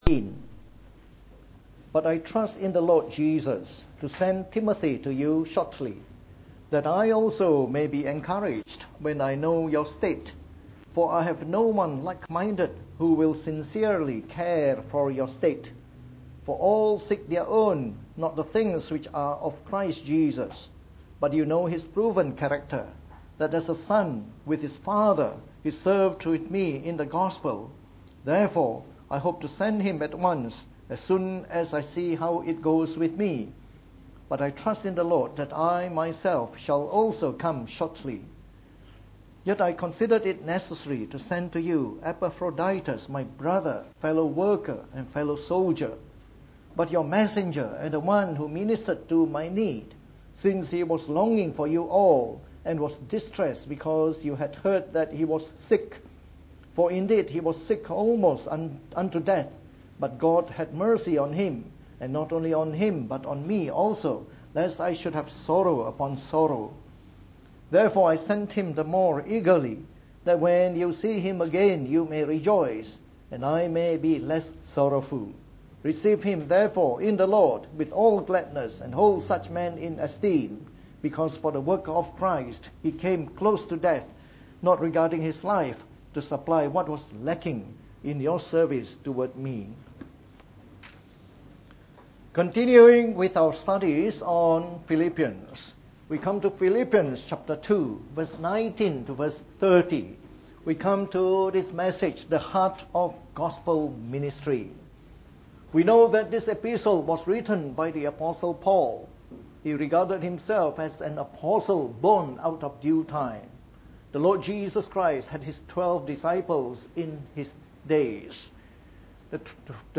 From our series on the Epistle to the Philippians delivered in the Morning Service.